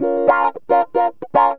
GTR 10A#M110.wav